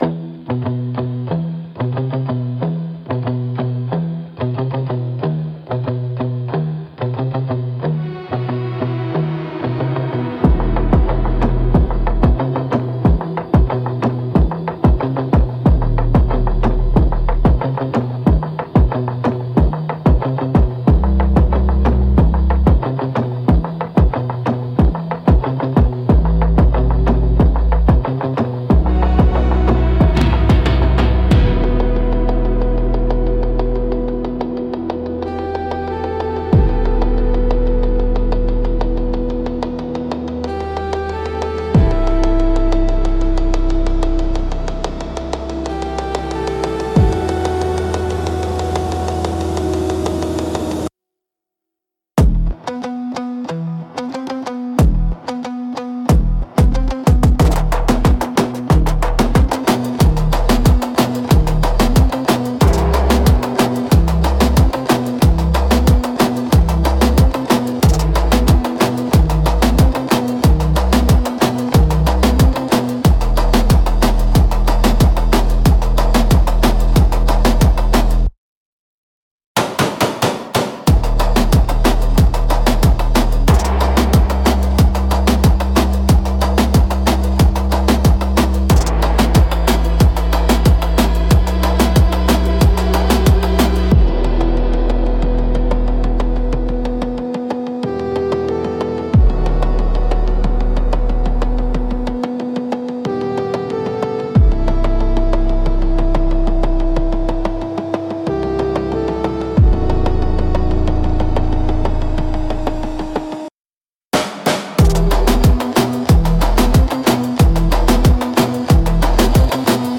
• Defined the audio direction: dark synthwave with cinematic trailer build-ups, heavy bass, and electronic accents.